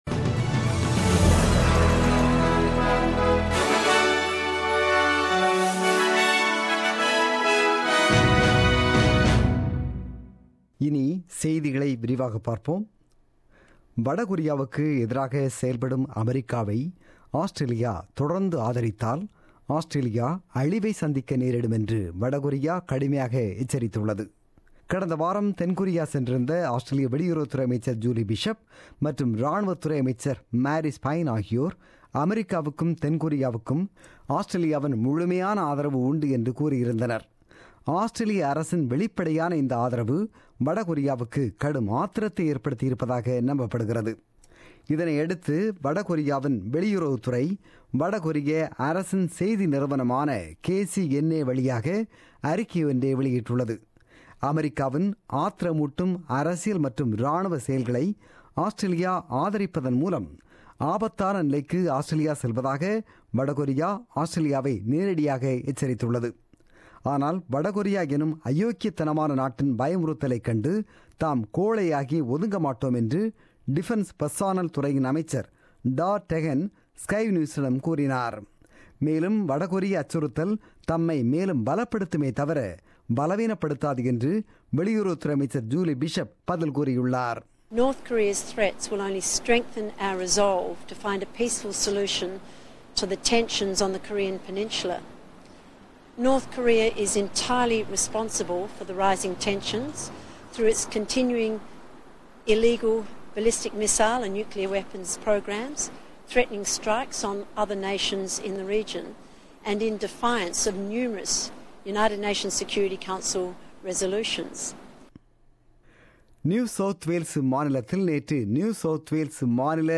The news bulletin broadcasted on 15 October 2017 at 8pm.